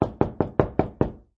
SFX敲门声音效下载